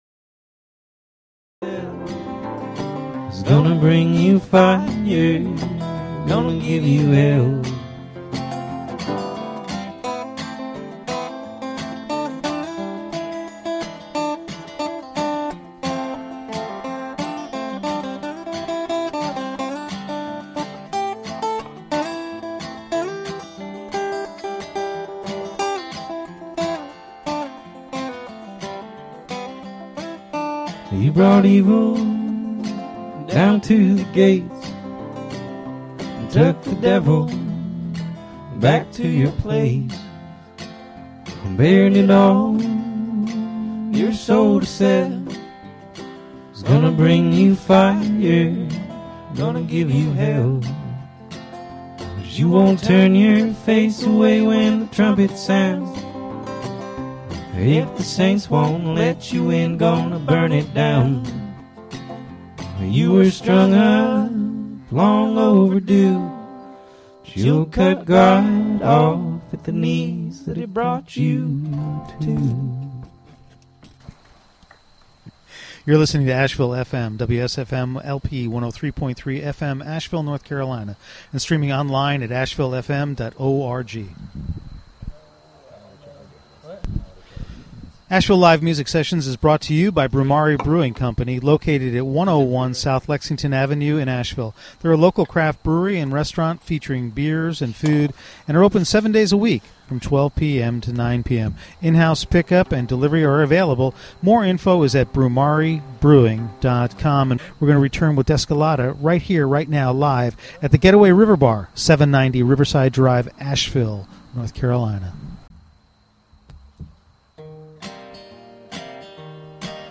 Live from The Getaway River Bar